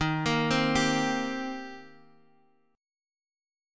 D#6 Chord